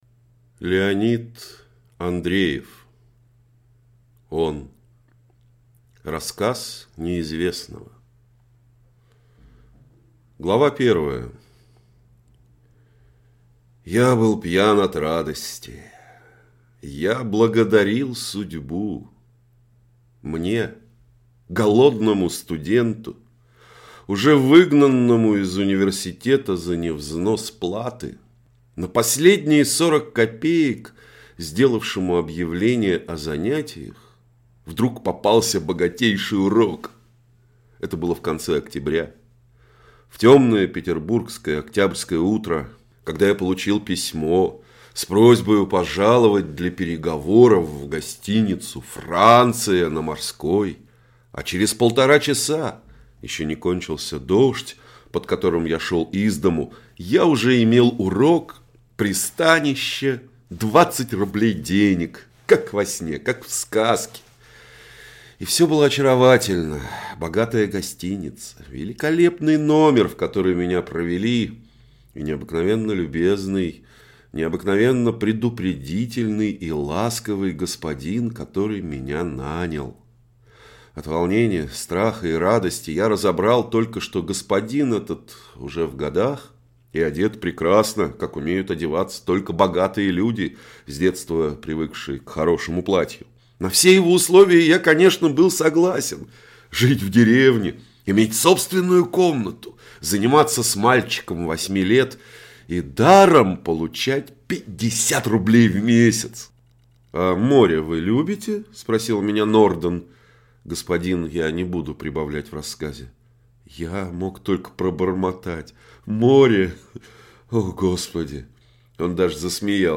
Аудиокнига Он | Библиотека аудиокниг